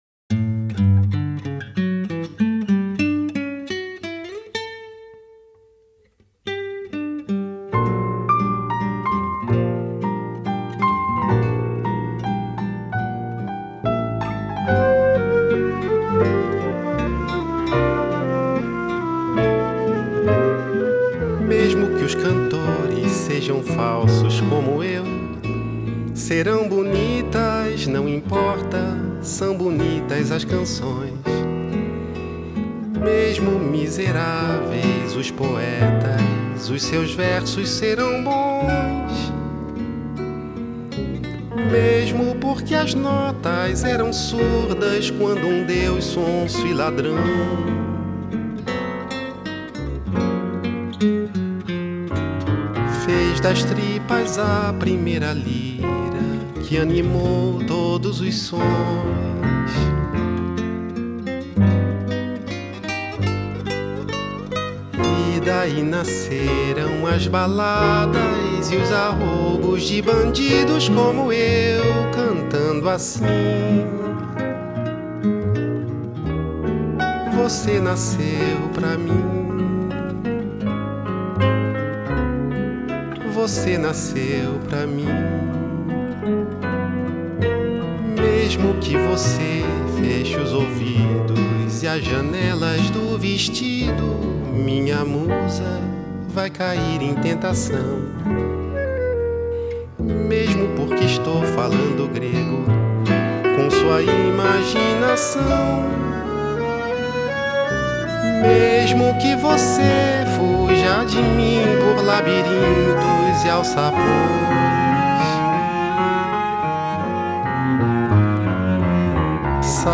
is a Brazilian musical style